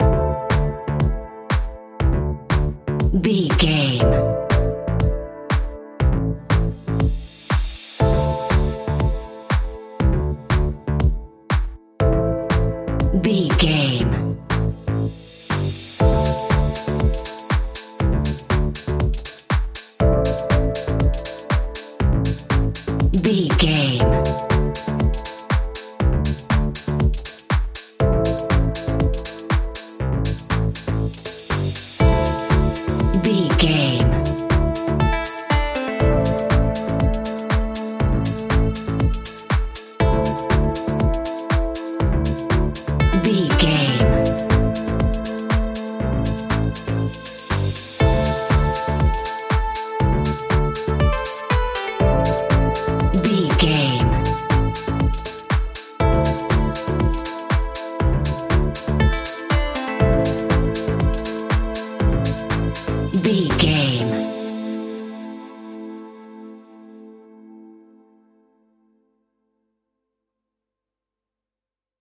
royalty free music
Aeolian/Minor
groovy
uplifting
energetic
bass guitar
strings
electric piano
synthesiser
drum machine
funky house
electro
nu disco
upbeat
instrumentals